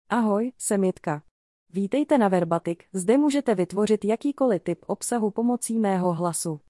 JitkaFemale Czech AI voice
Jitka is a female AI voice for Czech.
Voice sample
Listen to Jitka's female Czech voice.
Female